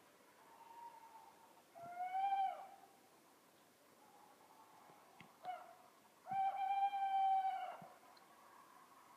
Ugle? 2
Er forøvrig to fugler som kan høres i dette lydklippet - en som svarer i bakgrunnen, så regner med de er samme art?
Glemte å nevne på forrige innsending at lydklippene er tatt opp i England.
Her høres ropet til kattugle hannen. Det er usikkert om ropet i bakgrunnen kommer fra en annen hann, eller om det kan være hunnen som lager hannlignende rop (det kan de også gjøre).